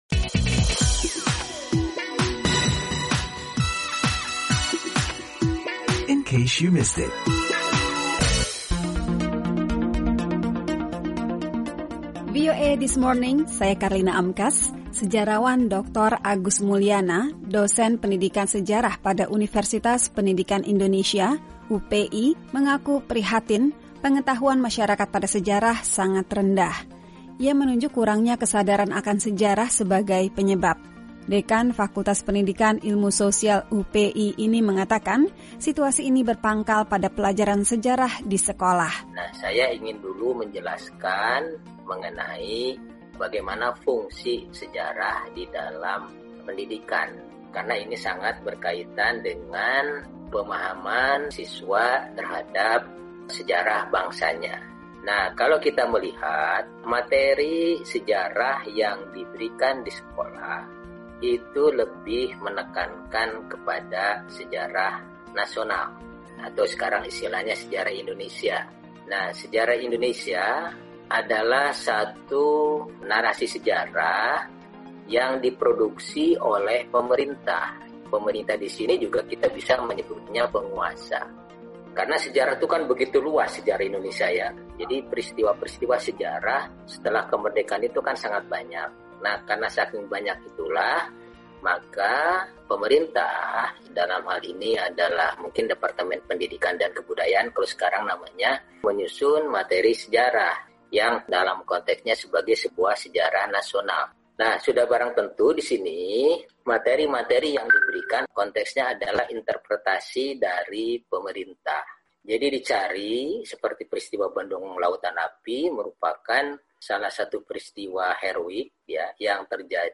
Reporter VOA